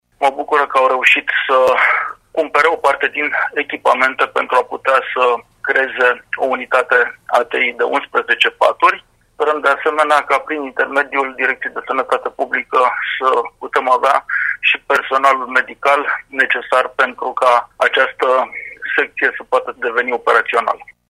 Pentru achiziția aparaturii și a echipamentelor destinate secției ATI de la Spitalul de Psihiatrie, fondurile au fost alocate de administraţia judeţeană. Preşedintele Consiliului Judeţean Braşov, Adrian Veștea: